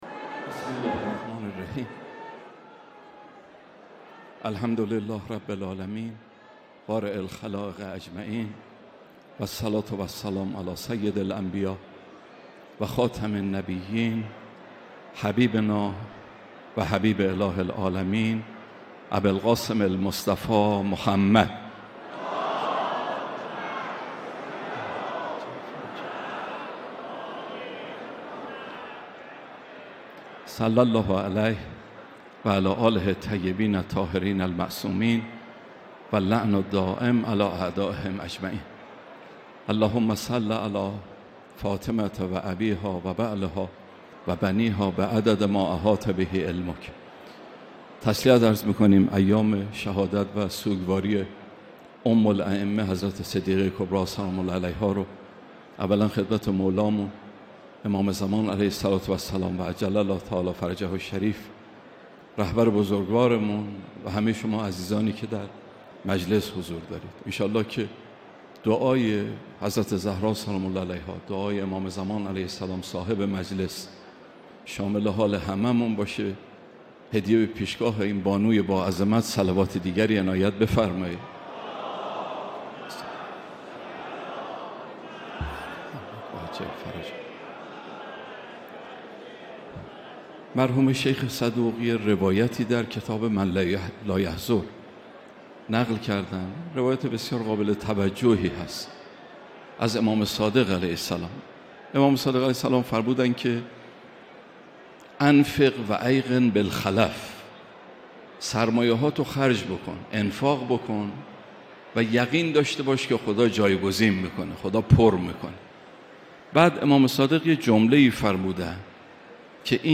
دومین شب مراسم عزاداری حضرت زهرا سلام‌الله‌علیها در حسینیه امام خمینی(ره)
سخنرانی